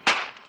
Projectile Sand Impact Sound.wav